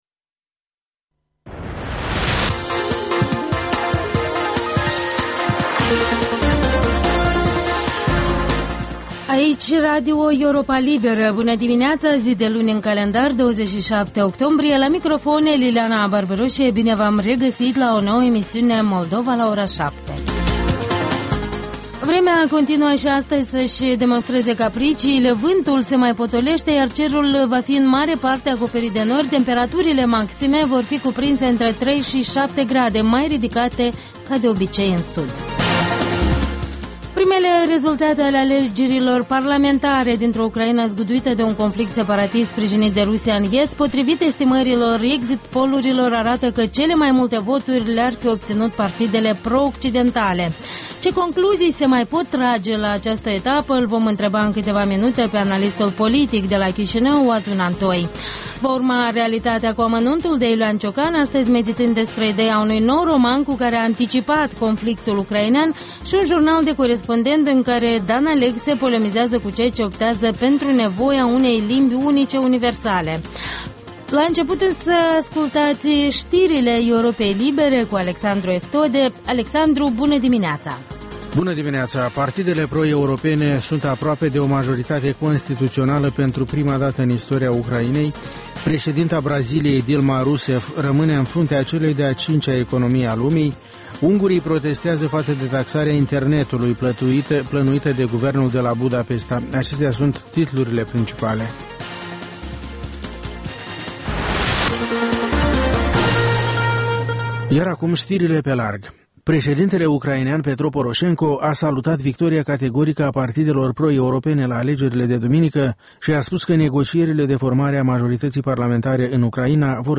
Ştiri, interviuri, analize.